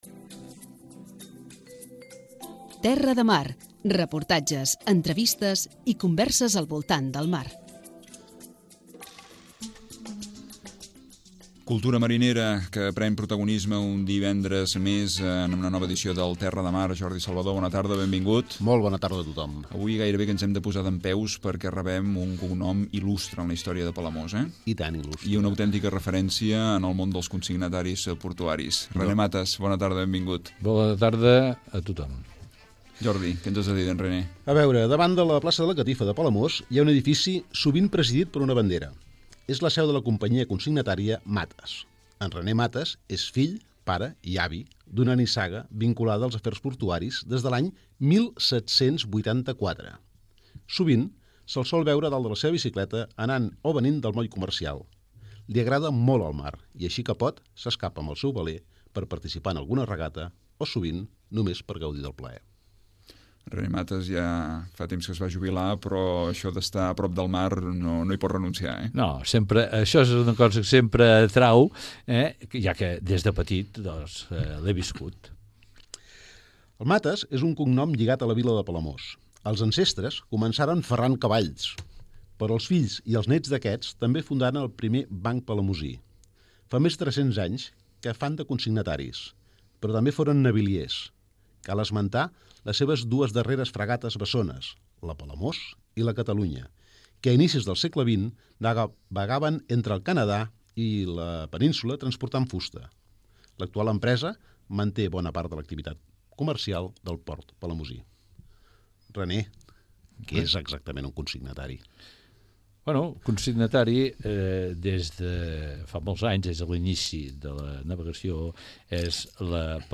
Rebem la visita als estudis de Ràdio Palamós